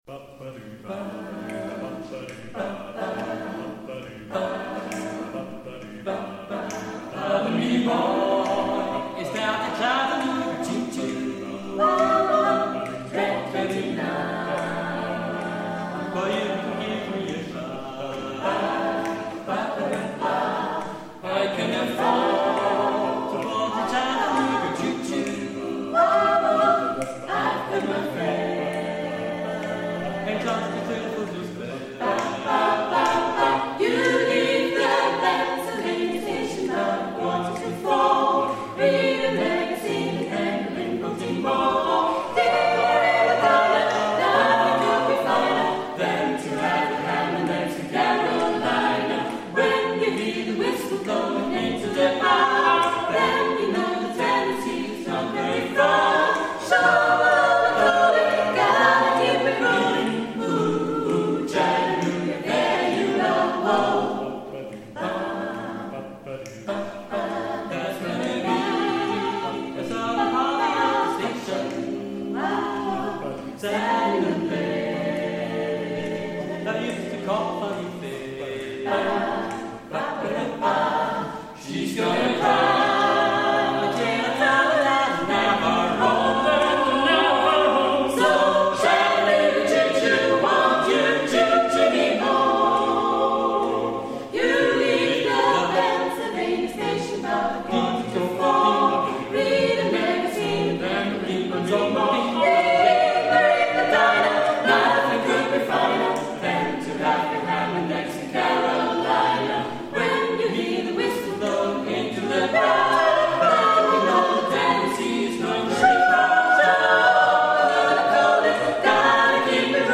Pop/Rock/Gospel/Jazz